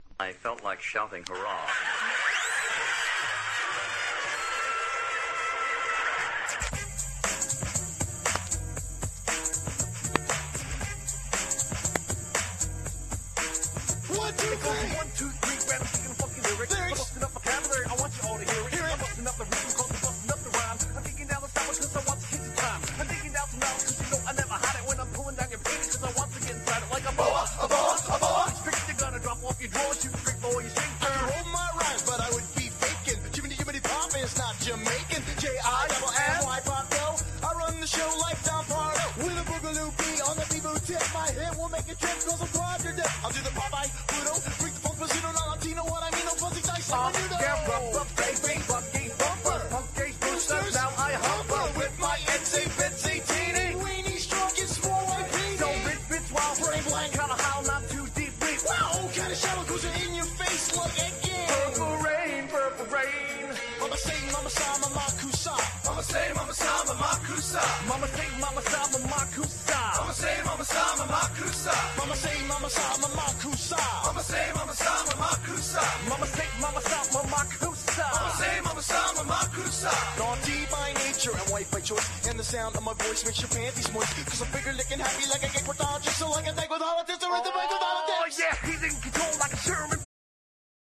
歯切れのいいキャッチーなラップで上げまくるパーティー・チューン！！